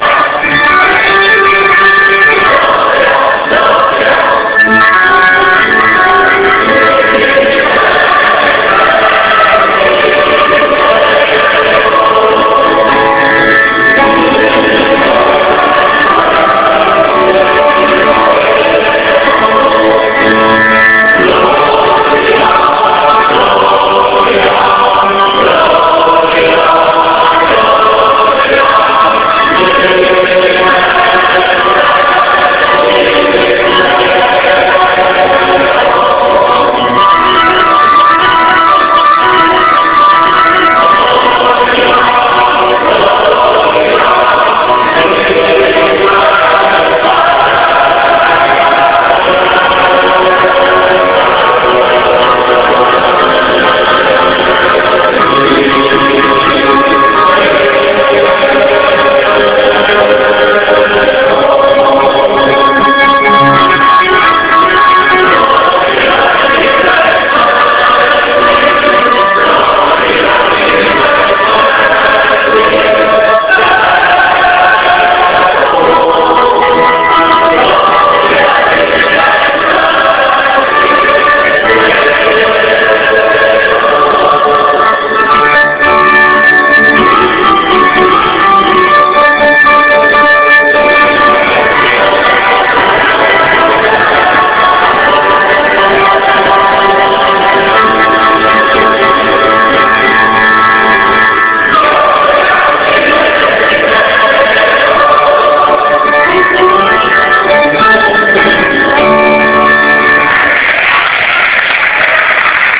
Le Festival interr�gional de chant choral de l'UAICF avait lieu � Gardanne.
Ecouter le Gloria (chant commun)